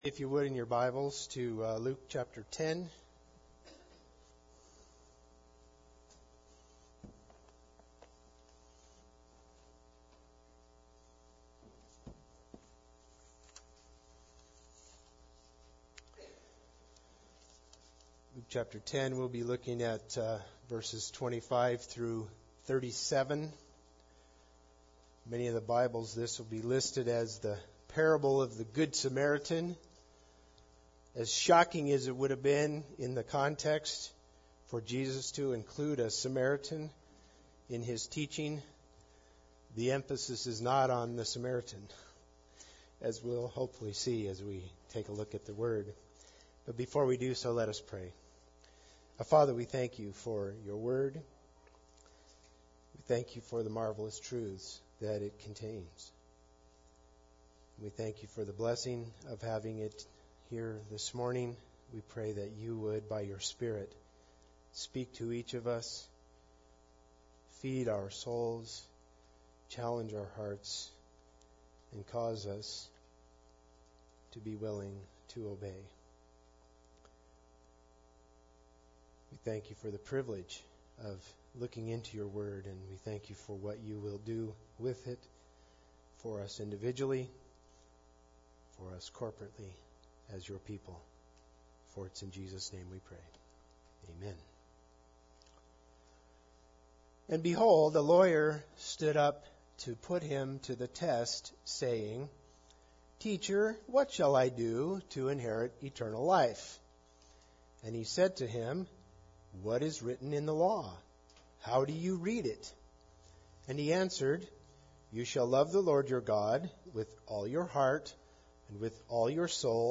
Preacher
Service Type: Sunday Service